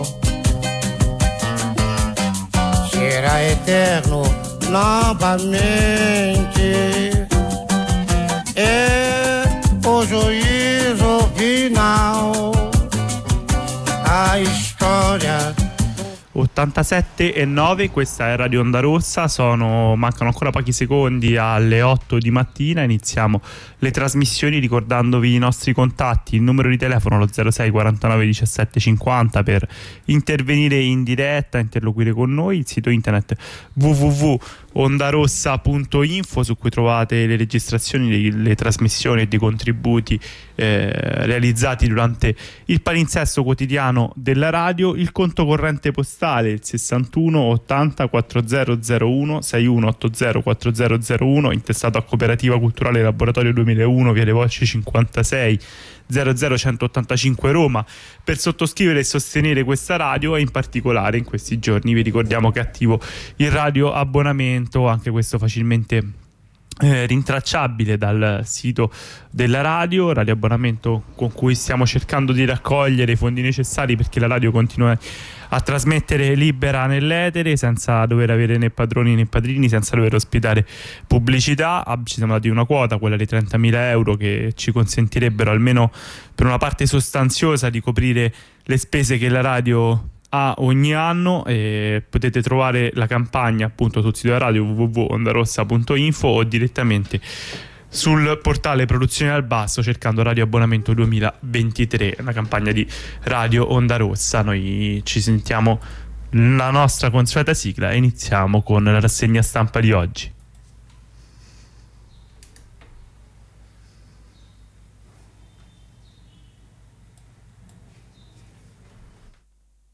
la lettura quotidiani di oggi